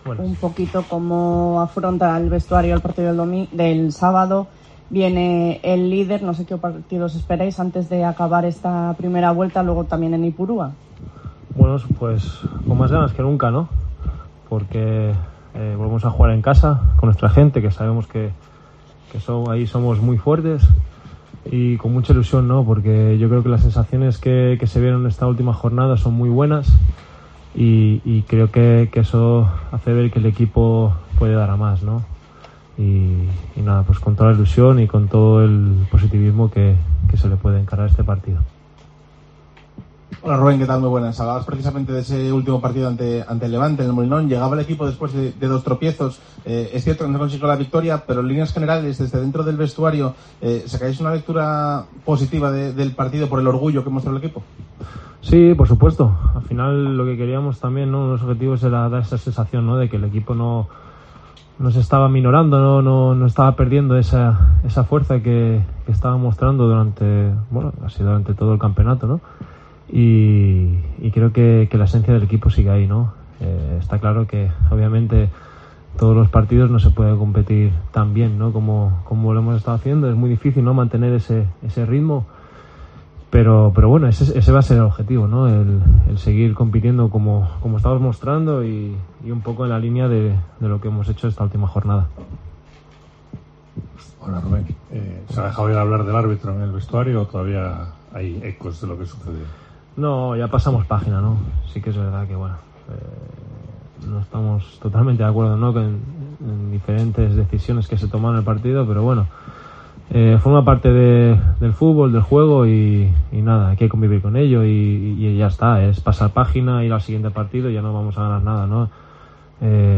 "Creemos que somos los más fuertes en El Molinón y queremos demostrarlo; demostrar que podemos estar arriba", ha dicho el guardameta antes de recibir al Leganés.